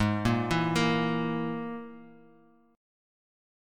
Absus2#5 Chord